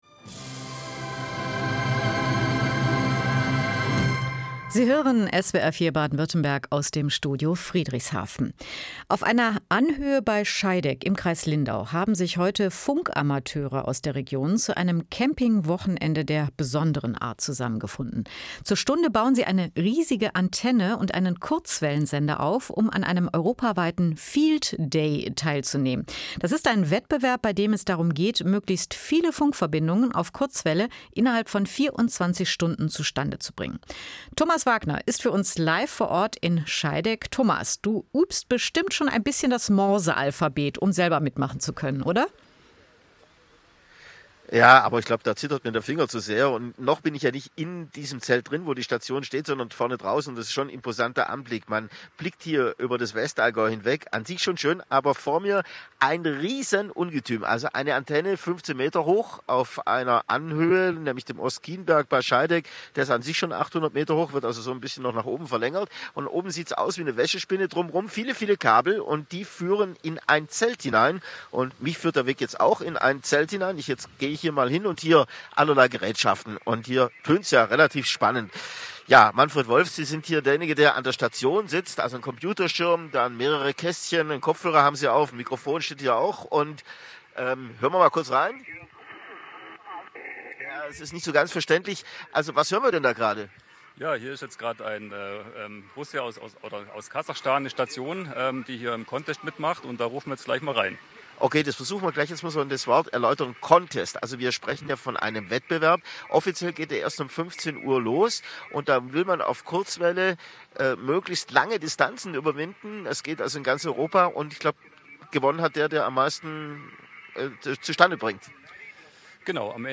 Live-Mitschnitt der SWR4 Sendung: